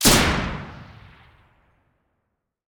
generalgun.ogg